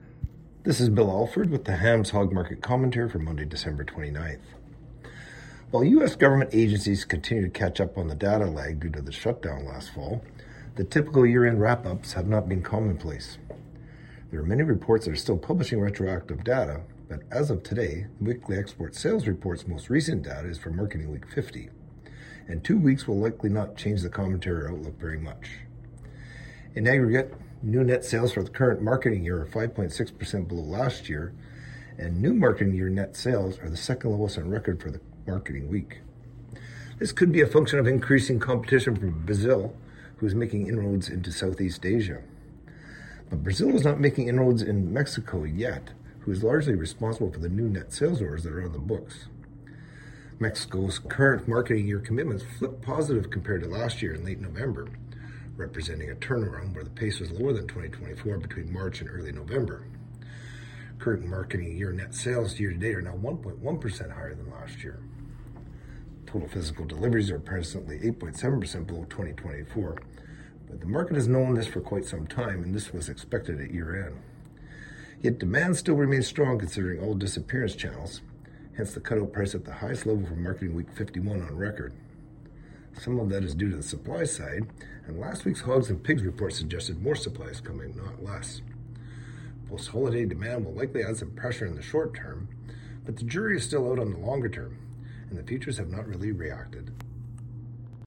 Hog-Market-Commentary-Dec.-29-25.mp3